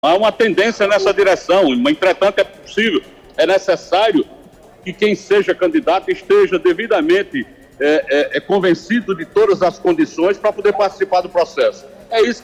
O governador da Paraíba, João Azevêdo (PSB) afirmou durante entrevista a Arapuan FM no início da noite desta quarta-feira que há uma forte tendência para que a composição da chapa com o deputado federal Aguinaldo Ribeiro (PP) para o Senado Federal se consolide, porém, mais uma vez cobrou um posicionamento do candidato progressista.